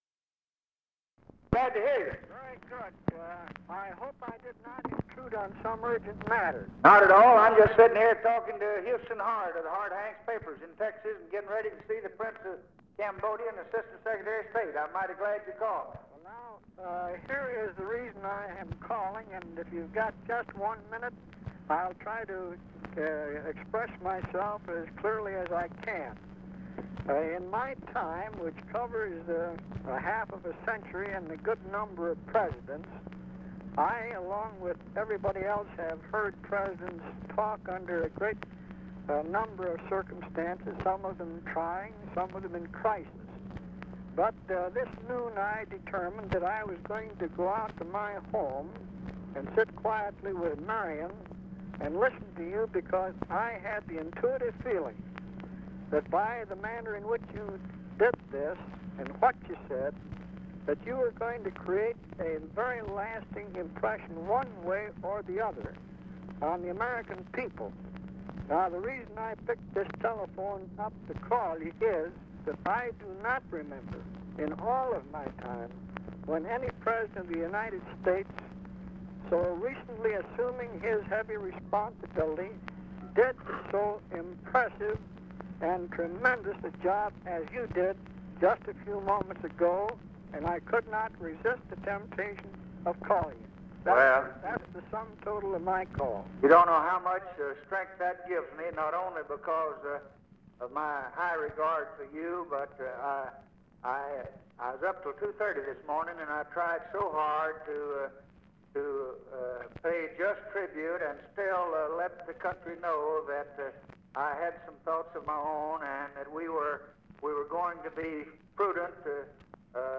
Conversation with LOUIS SELTZER, November 27, 1963
Secret White House Tapes